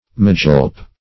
Magilp \Ma*gilp"\, Magilph \Ma*gilph"\, n. (Paint.)